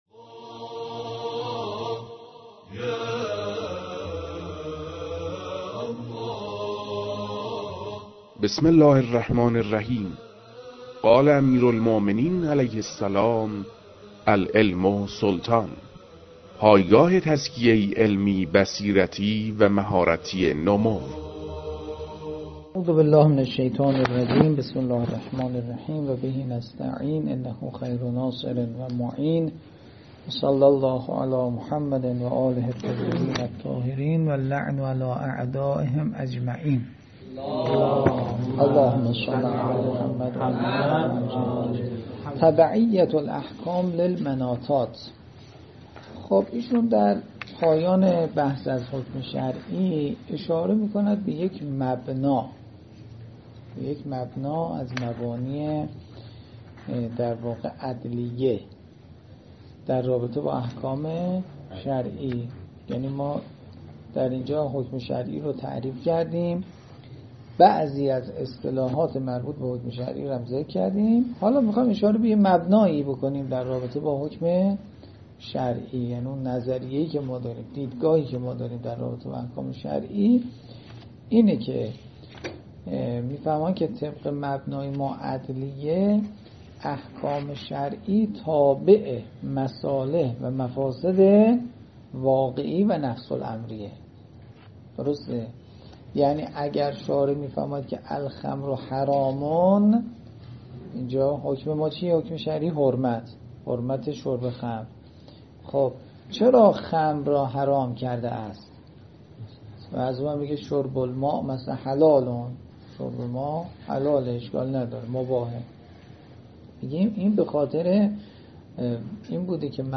در این بخش، کتاب «الاساس» که اولین کتاب در مرحلۀ آشنایی با علم اصول فقه است، به صورت ترتیب مباحث کتاب، تدریس می‌شود.
در تدریس این کتاب- با توجه به سطح آشنایی کتاب- سعی شده است، مطالب به صورت روان و در حد آشنایی ارائه شود.